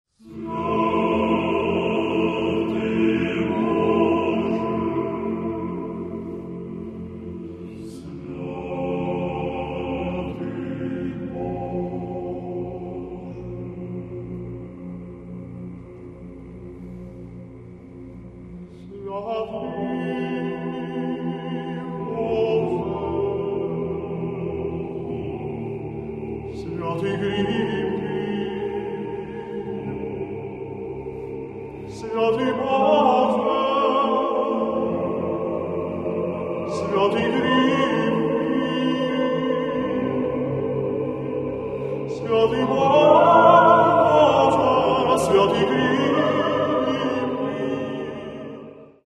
Catalogue -> Classical -> Choral Art